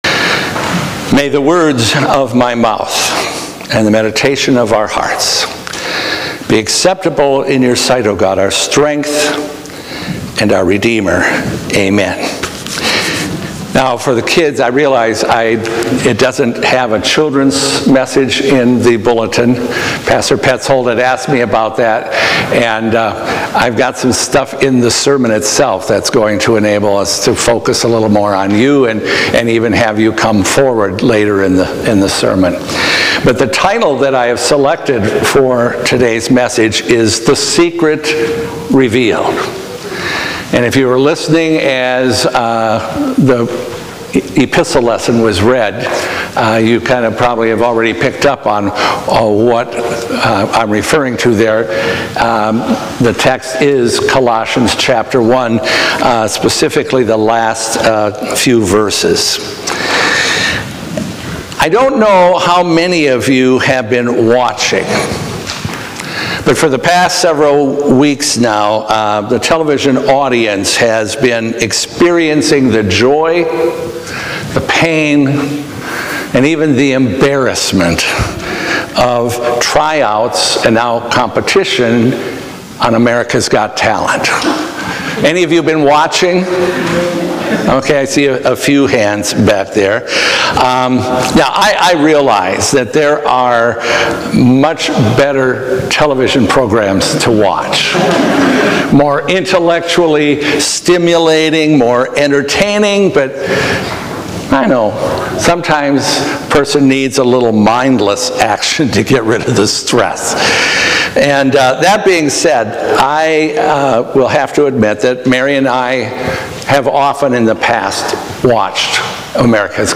This sermon explores God's profound secret, now unveiled through Jesus Christ: humanity's reconciliation with Him. It emphasizes that salvation is by grace through faith in Christ's suffering, death, and resurrection, not by works or adherence to rules.